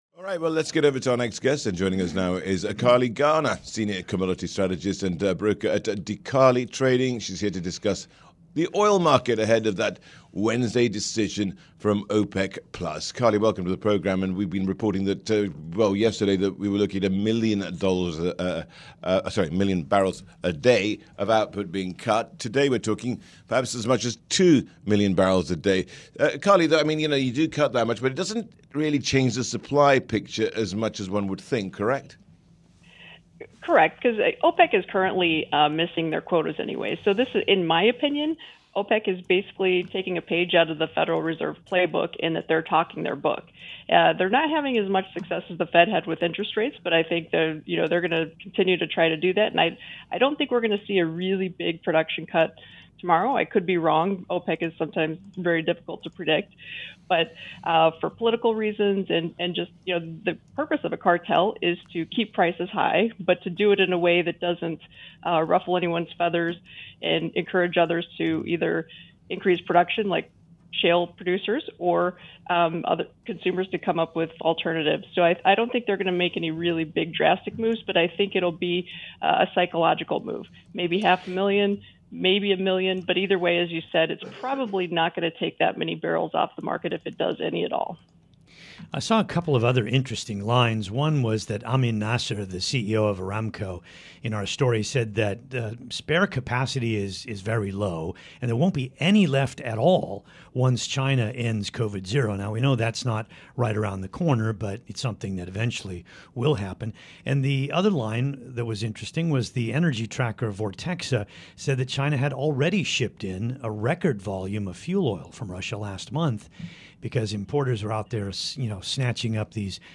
on Bloomberg Radio